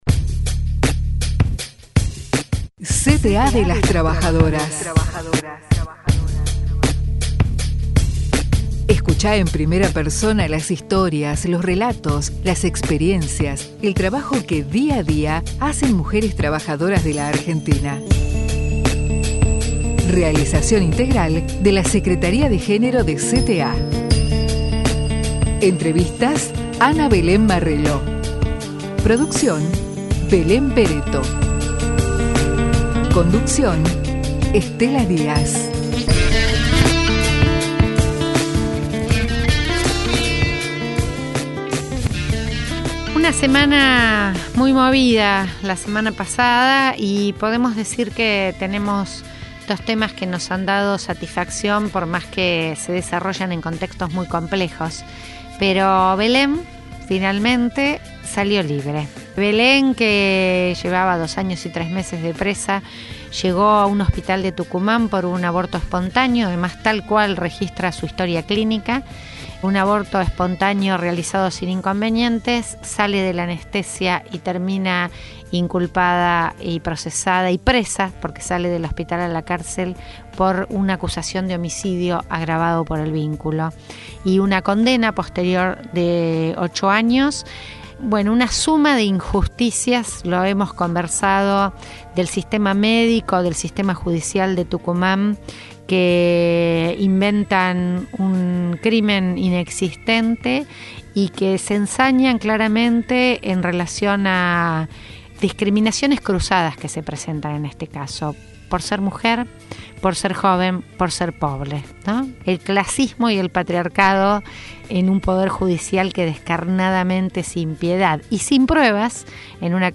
Palabras de Hugo Yasky en el cierre de la gran Marcha Federal en Plaza de Mayo.